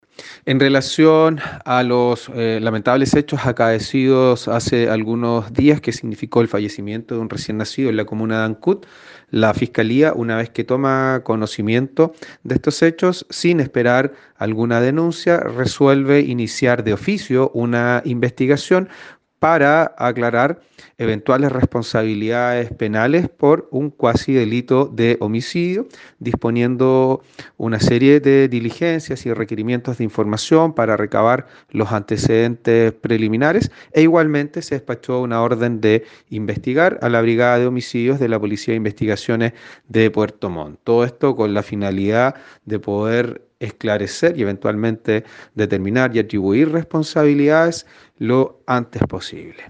Escuchemos lo señalado por el fiscal Fernando Metzner al respecto.